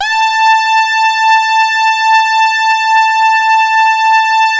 81-TARKUS A4.wav